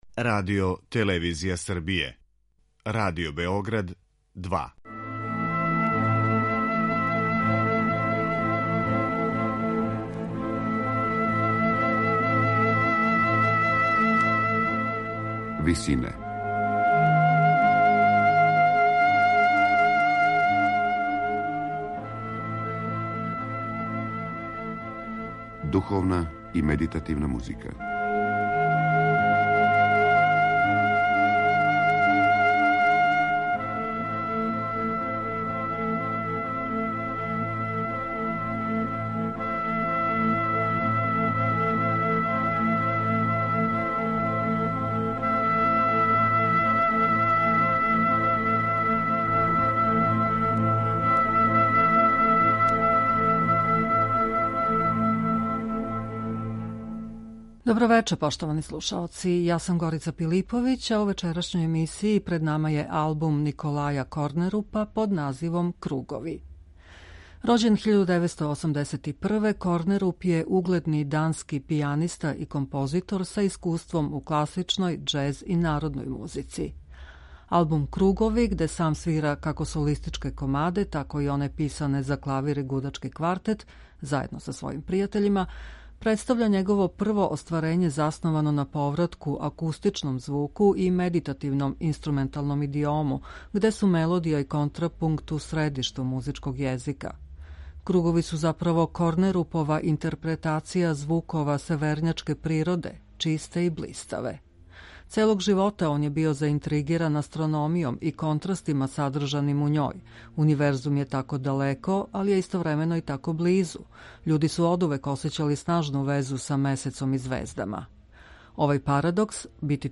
медитативне и духовне композиције